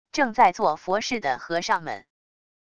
正在做佛事的和尚们wav音频